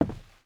Footsteps_Wood_Walk_02.wav